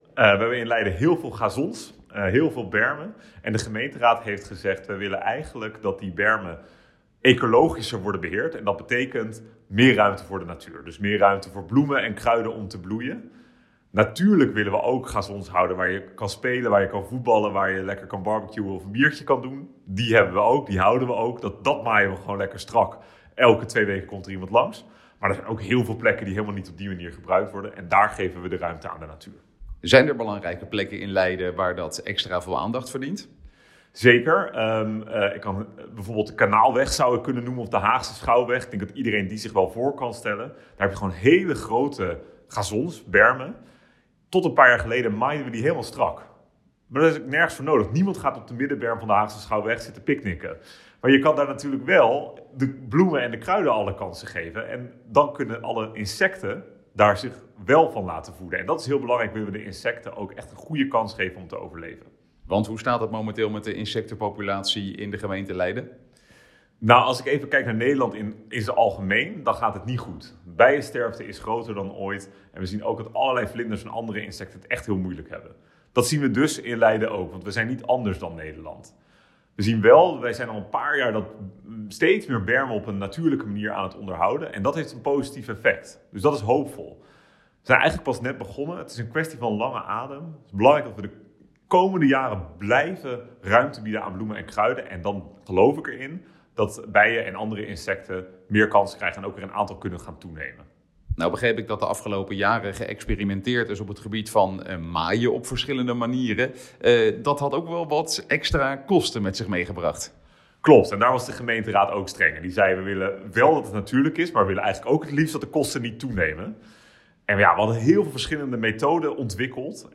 in gesprek met wethouder Ashley North over het grasmaaien en de kosten